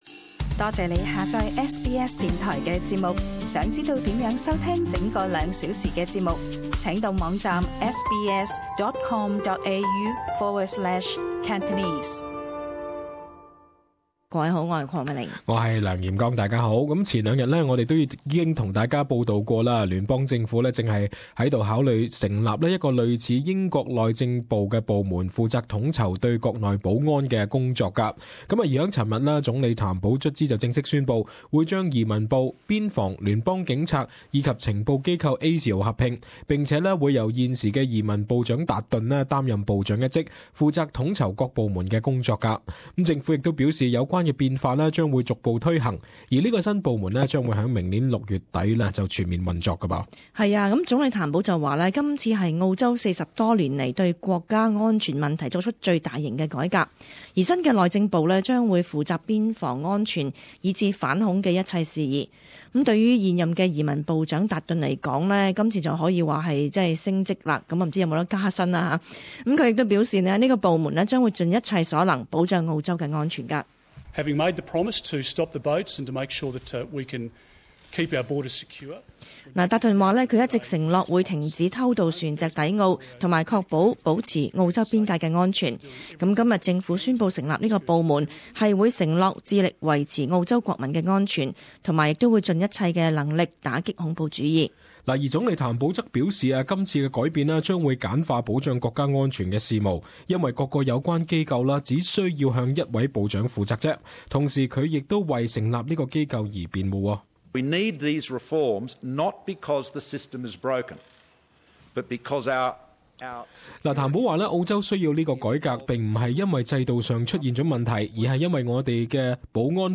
【時事報導】政府成立超級部門統籌國家安全事務